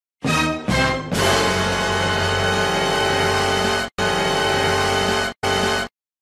Dramatic Sound Effect (DUN DUN DUUN!)